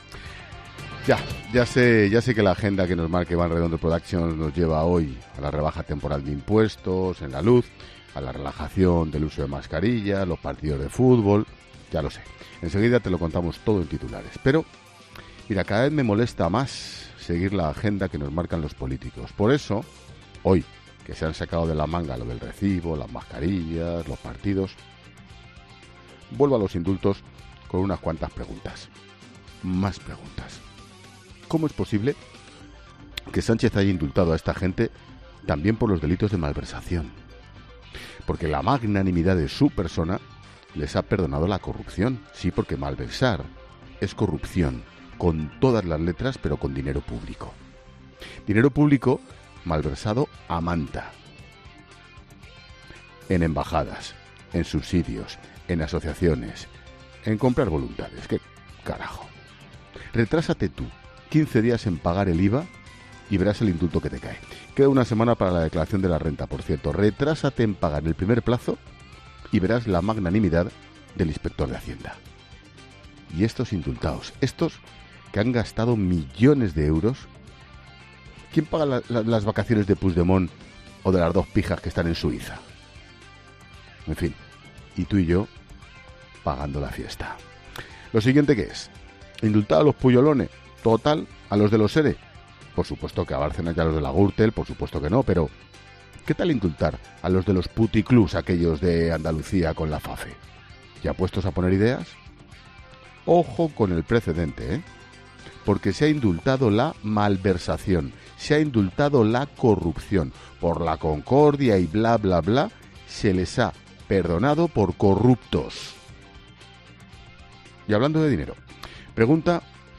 Monólogo de Expósito
El director de 'La Linterna', Ángel Expósito, reflexiona sobre las principales claves informativas que deja este jueves